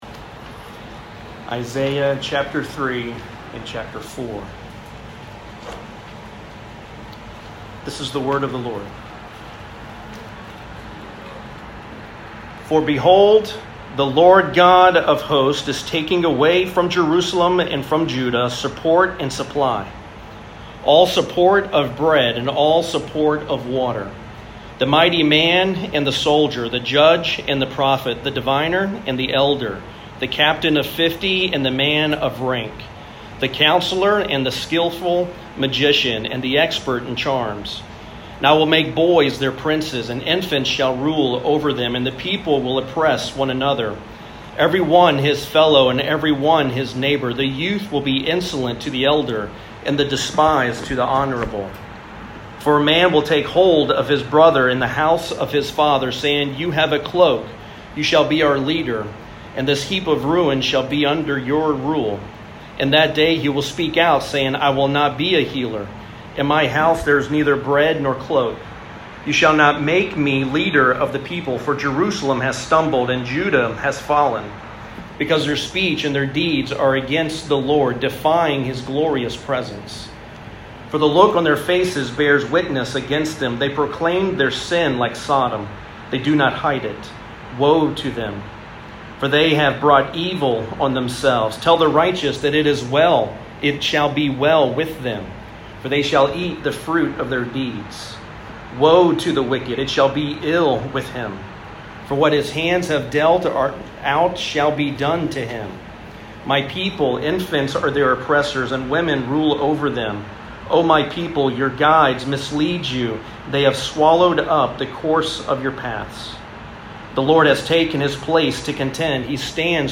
Sermon Outline: God prepares his people for transformation (Chapter 3) The people see their need for transformation (4:1) The Branch of the LORD provides the transformation (4:2-6)